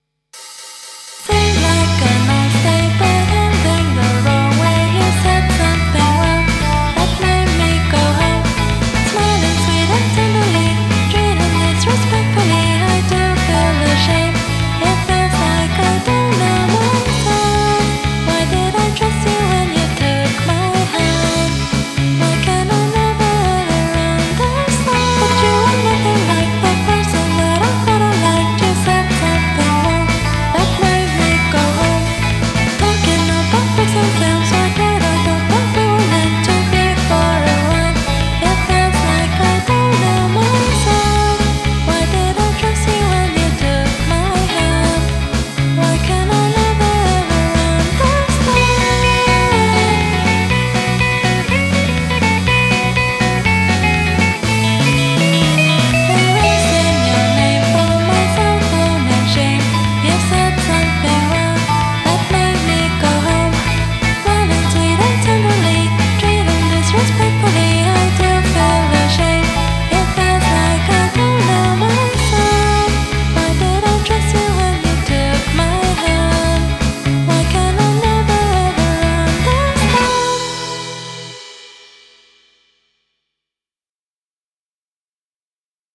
Take it from someone who doesn't really like most tweepop